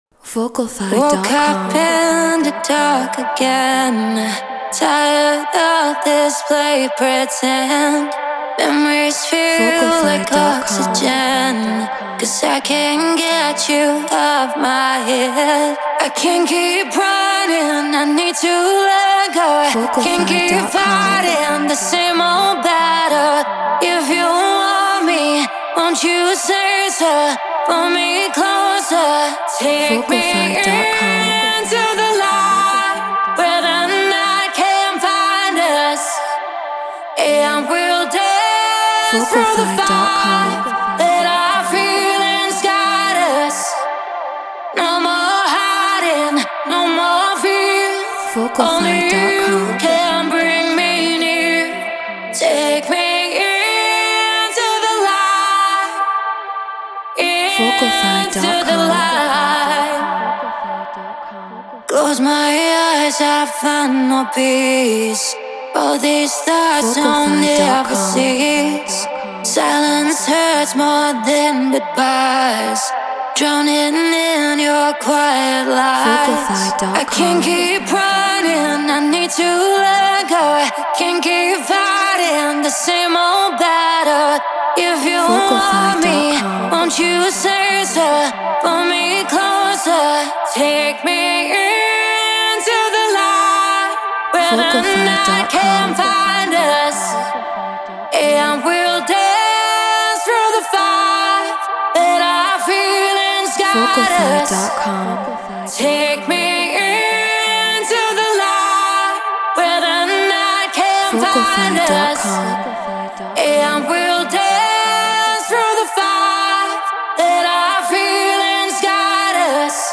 House 126 BPM Dmin
Treated Room